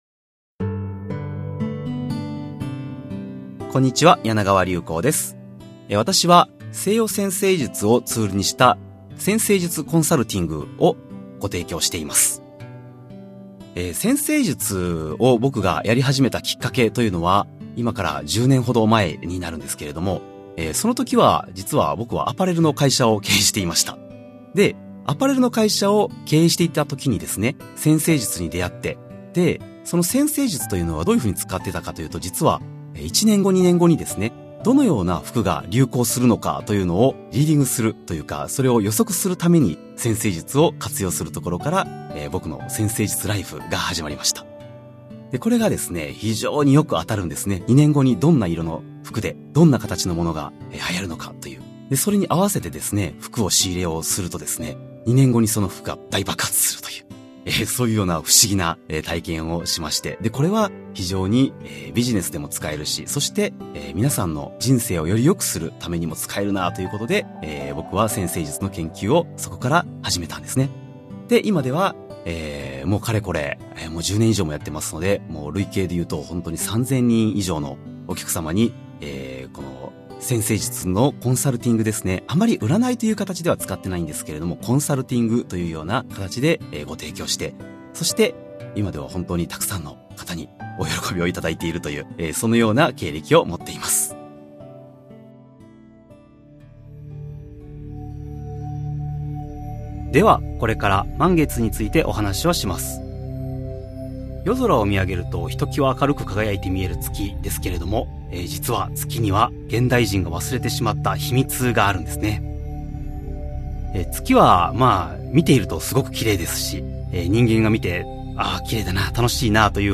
[オーディオブック] 聴いてわかる 月が導くあなたの願い 満月編